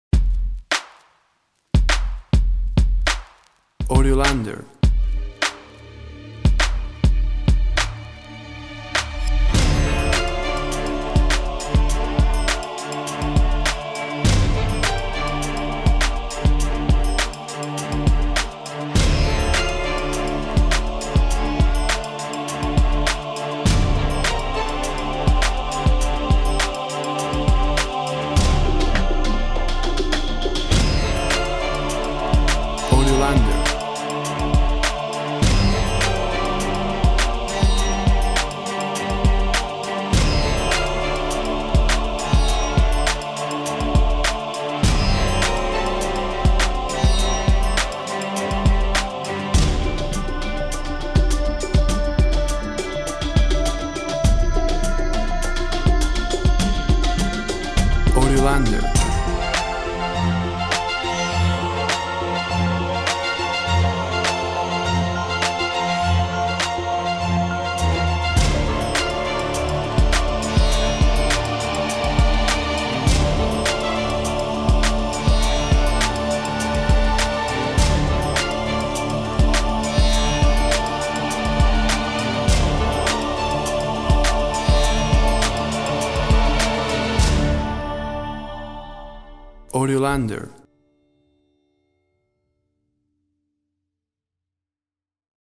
WAV Sample Rate 24-Bit Stereo, 44.1 kHz
Tempo (BPM) 104